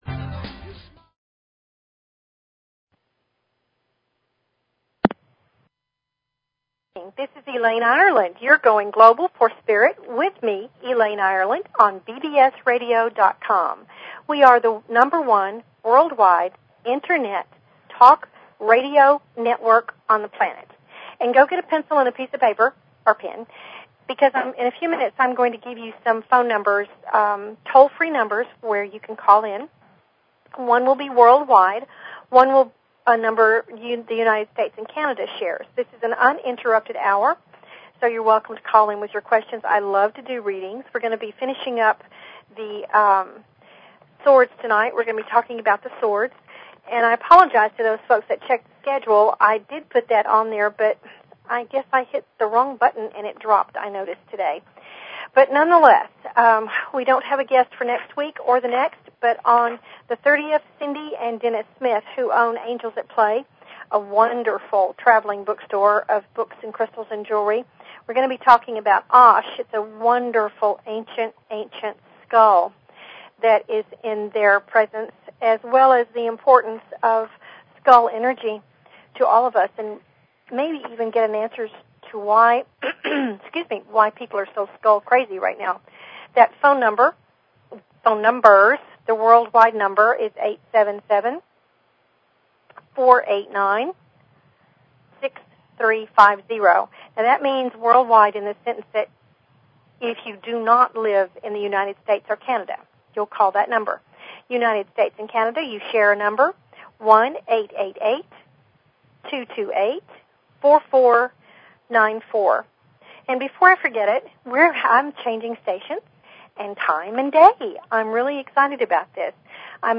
Talk Show Episode, Audio Podcast, Going_Global_for_Spirit and Courtesy of BBS Radio on , show guests , about , categorized as
Just me this evening. We will finish up a discussion about the Swords of the Ryder Waite Deck and talk about several other important happenings.